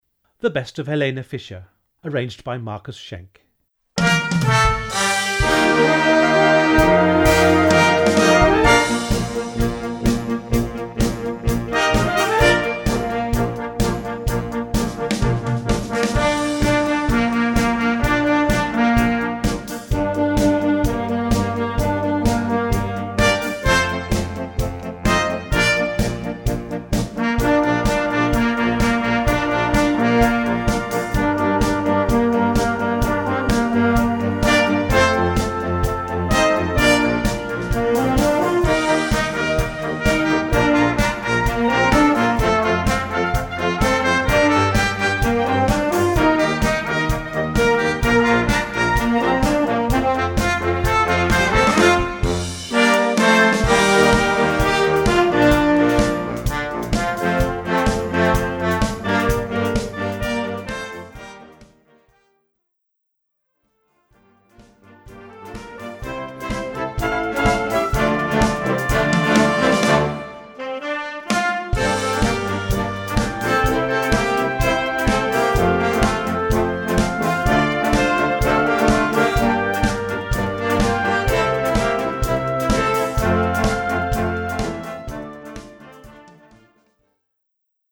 Gattung: Modernes Potpourri
Besetzung: Blasorchester